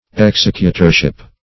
Executorship \Ex*ec"u*tor*ship\, n. The office of an executor.